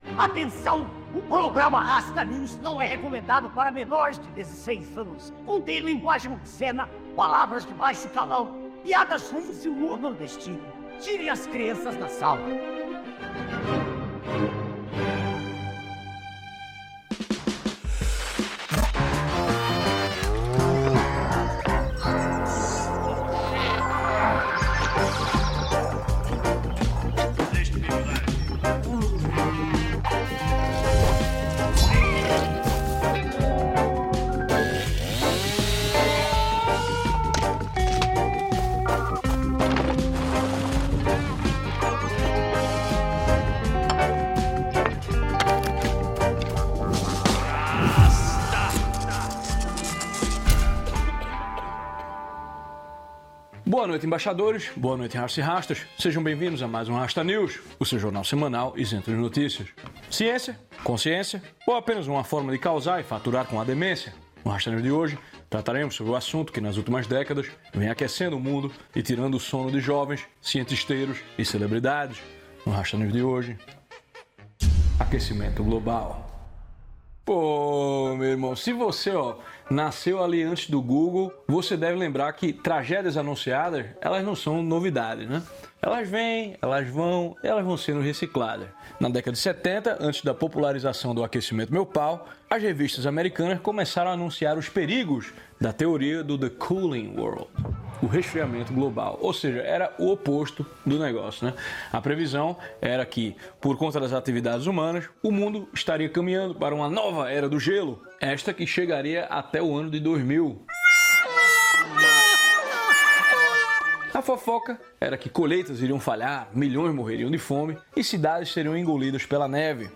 🐻 Um programa que mistura jornalismo e humor